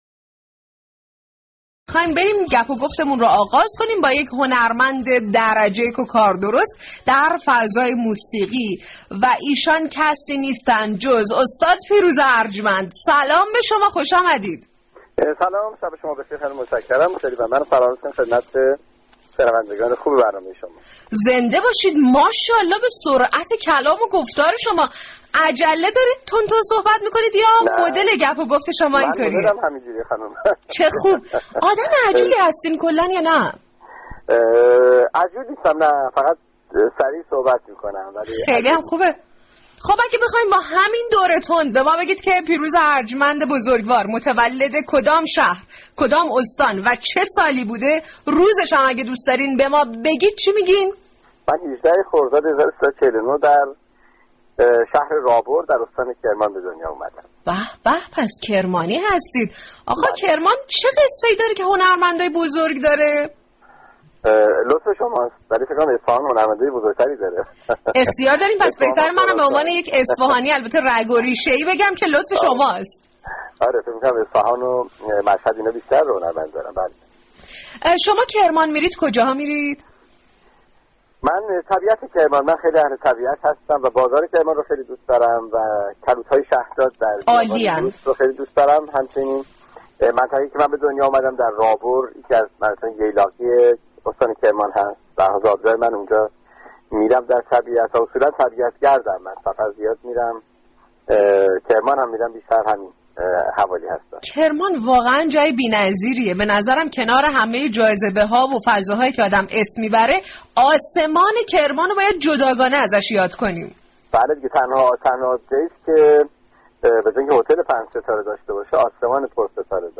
رادیو صبا در برنامه« صباهنگ» میزبان پیروز ارجمند موسیقی شناس خوب كشورمان بود.